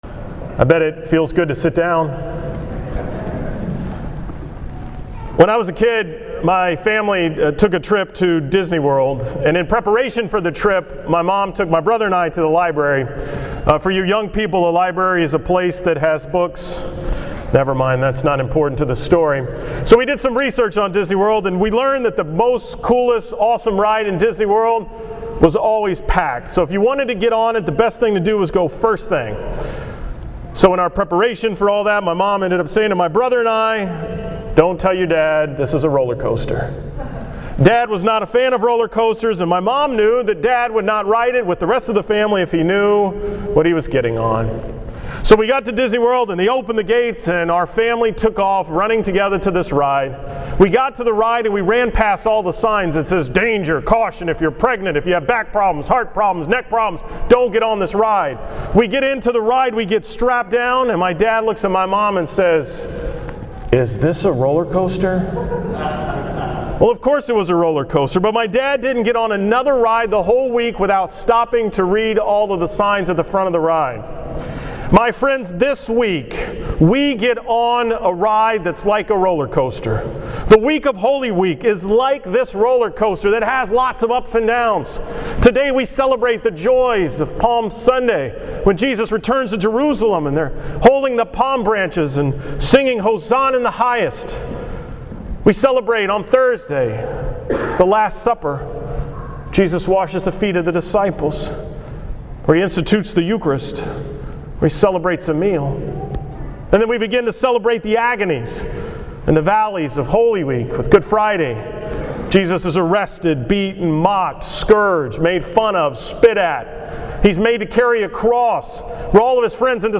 From the 7:30 am Mass on Palm Sunday